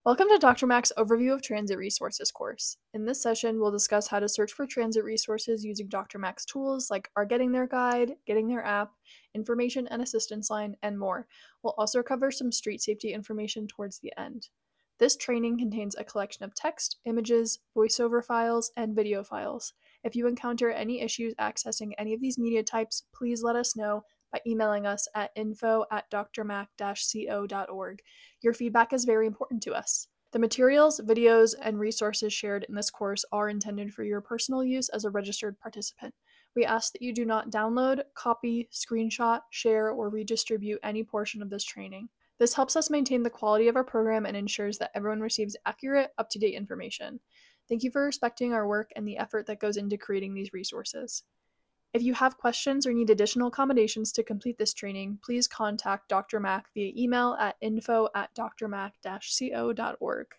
This training contains a collection of text, images, voiceover MP4 files, and video files.